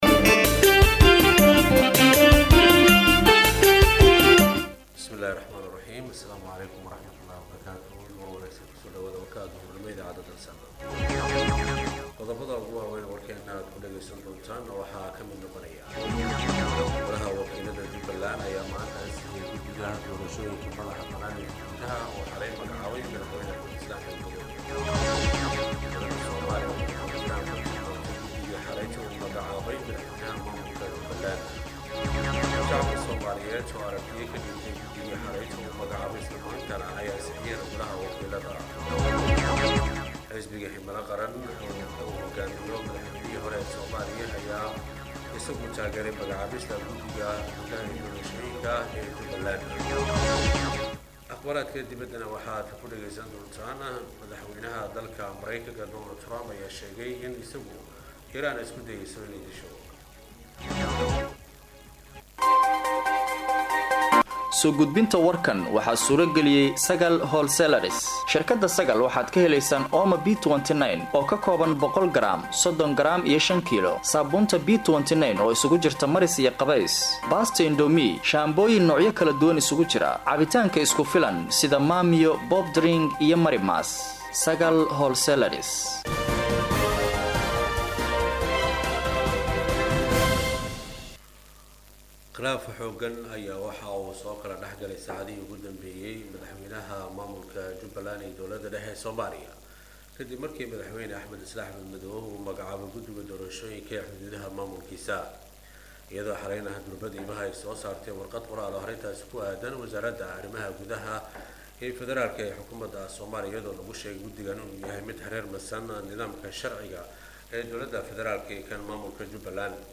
Dhageyso:-Warka Duhurnimo Ee Radio Dalsan 10/11/2024